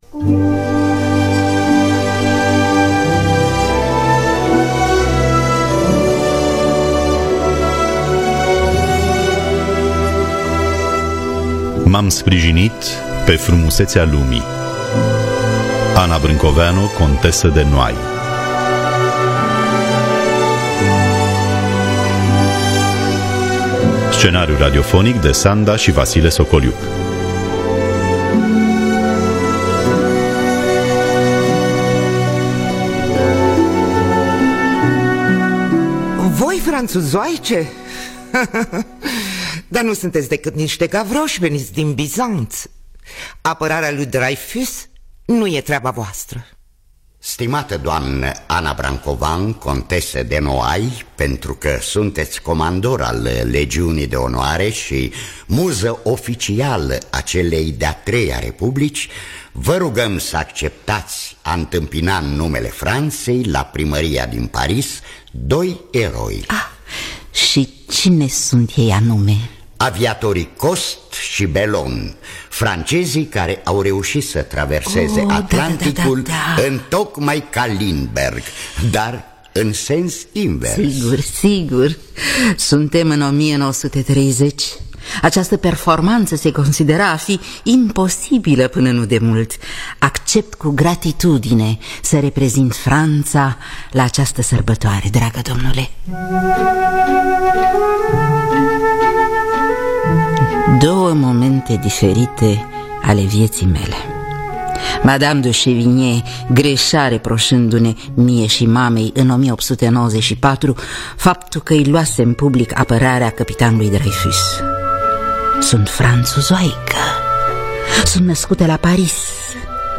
Biografii, Memorii: Anna Brancoveanu, Contesa de Noailles – M-am Sprijinit Pe Frumusetea Lumii – Teatru Radiofonic Online